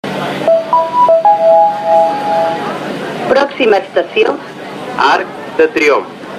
Metro-ProximaStationeArcDeTriomf.mp3